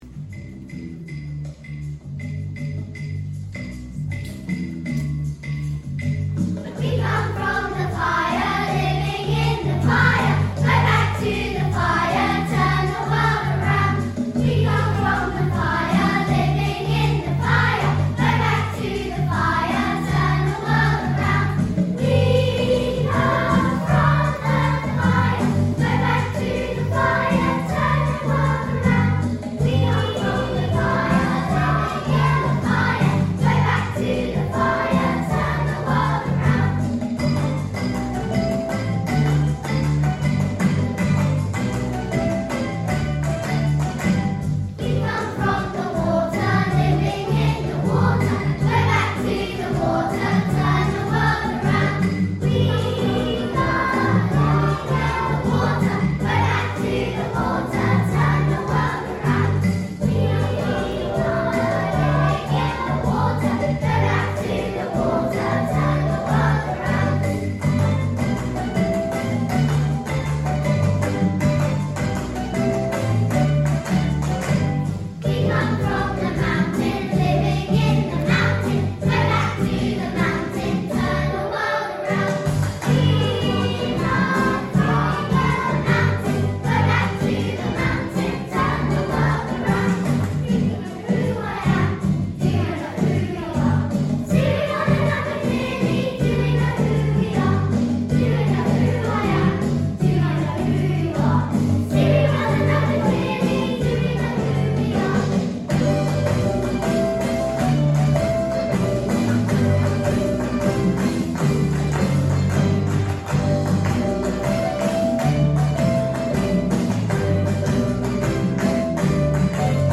Turn the World Around | Y4/5/6 Choir